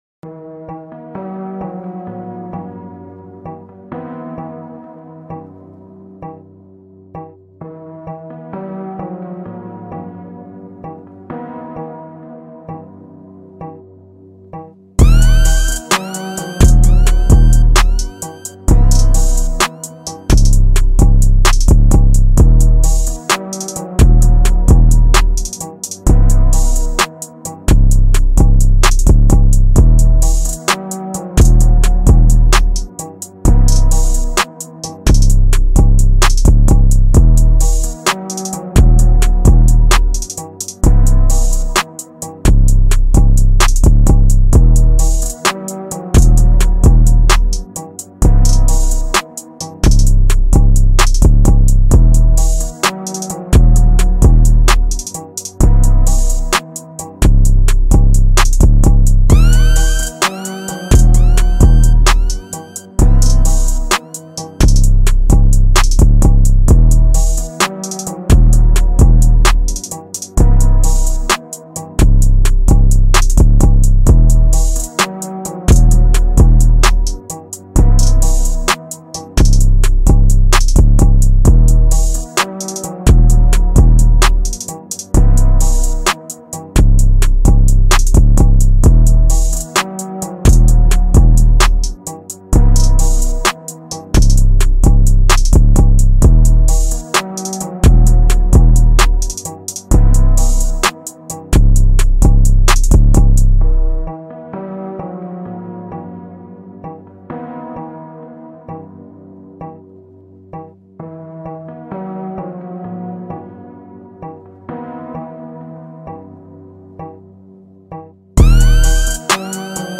official beat remake